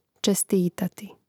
čestítati čestitati gl. dvov. prijel.
čestitati.mp3